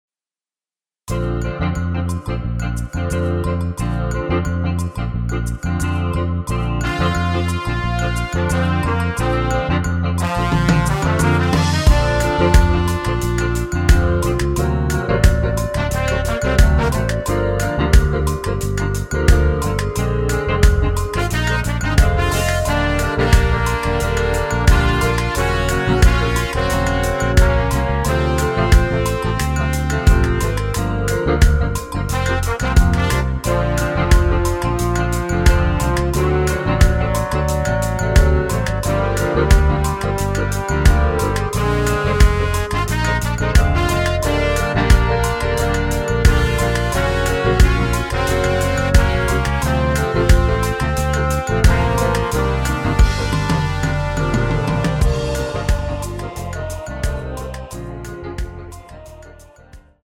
원키에서(-6)내린 멜로디 포함된 MR입니다.
엔딩이 길어 축가에 사용 하시기 좋게 엔딩을 짧게 편곡 하였습니다.(원키 코러스 버전 미리듣기 참조)
Bb
앞부분30초, 뒷부분30초씩 편집해서 올려 드리고 있습니다.
중간에 음이 끈어지고 다시 나오는 이유는